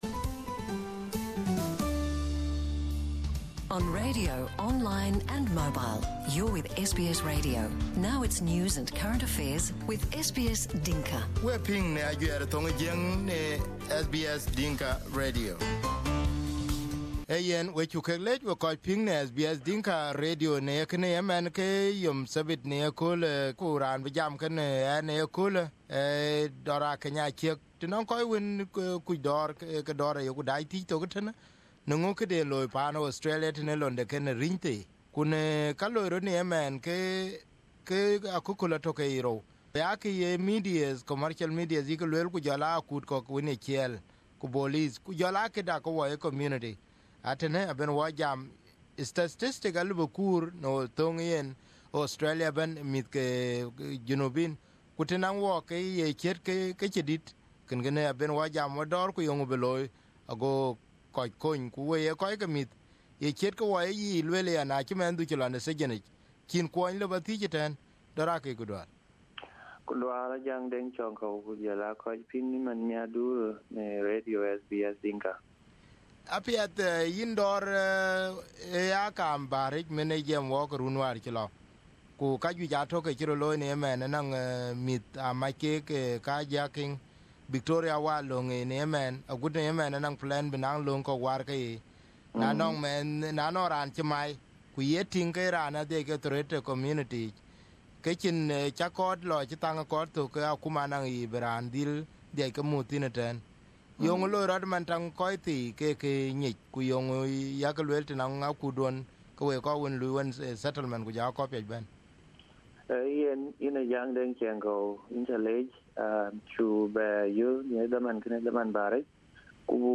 He believes the availability of those programs can keep you away from engaging in drugs or violence. Here is the interview